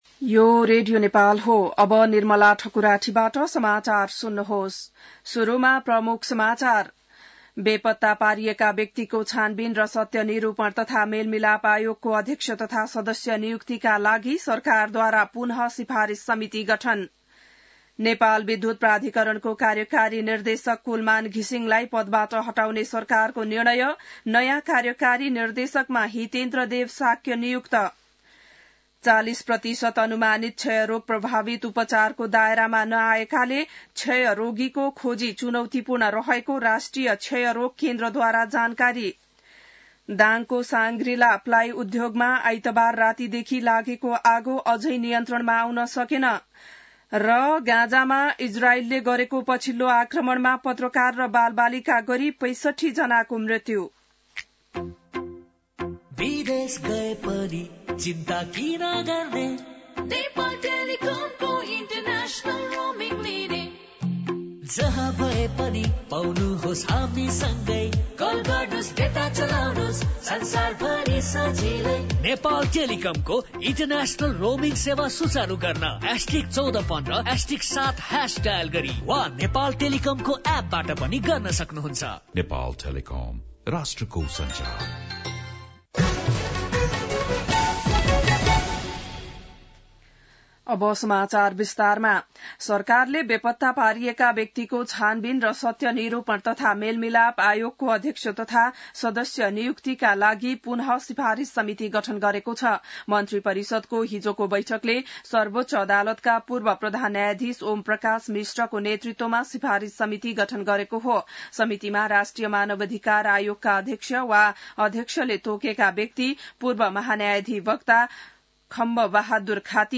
बिहान ७ बजेको नेपाली समाचार : १२ चैत , २०८१